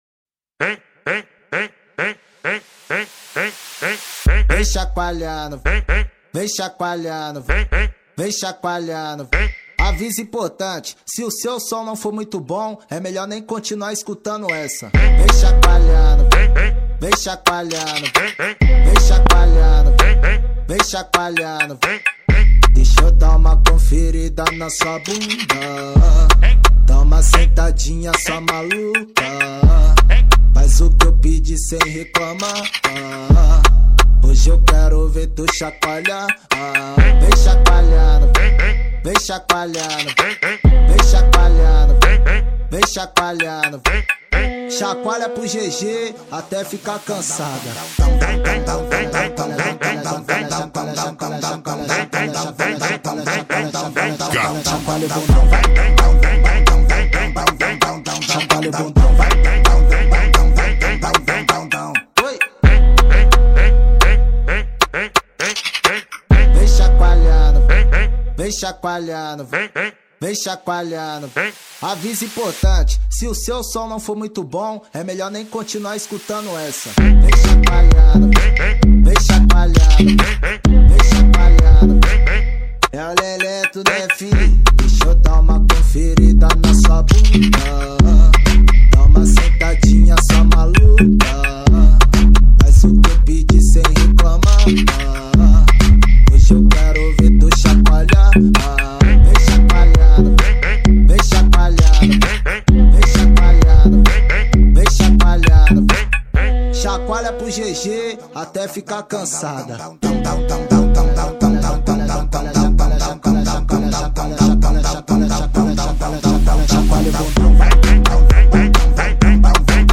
2024-06-28 11:22:18 Gênero: MPB Views